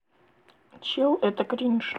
Кринж-сирена: леденящий вой